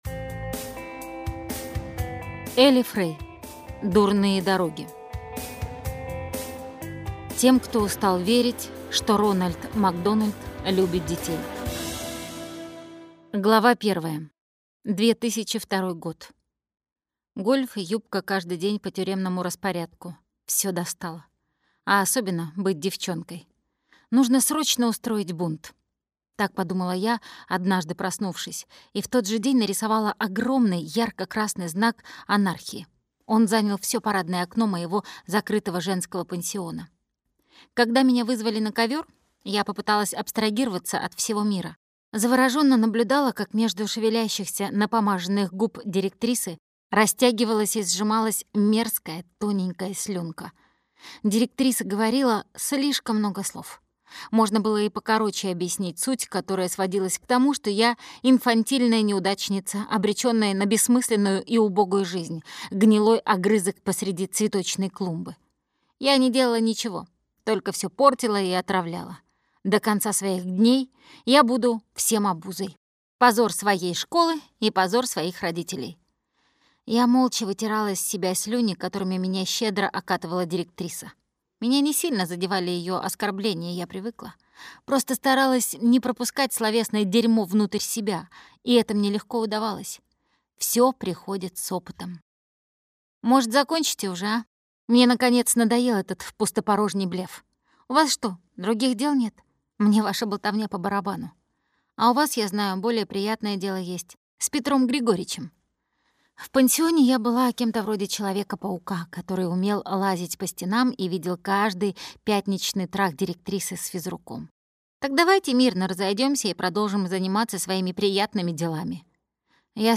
Аудиокнига Дурные дороги - купить, скачать и слушать онлайн | КнигоПоиск